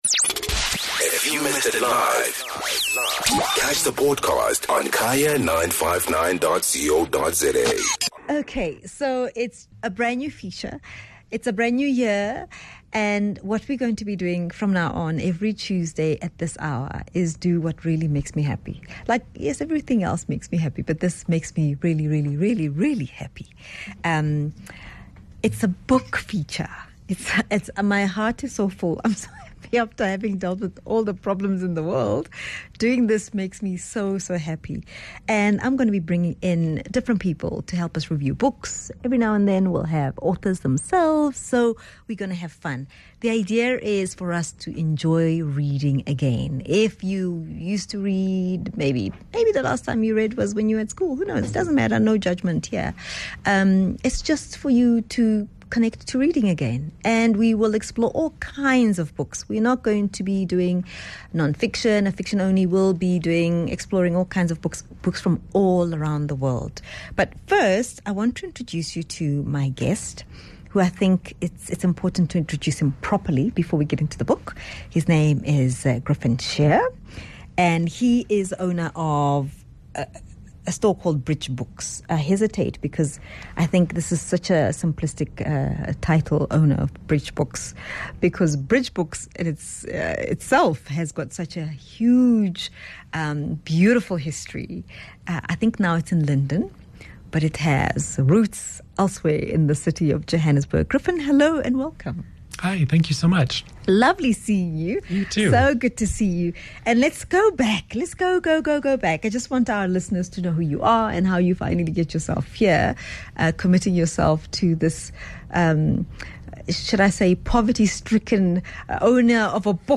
14 Jan BOOK REVIEW